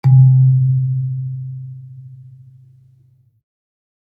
kalimba_bass-C2-pp.wav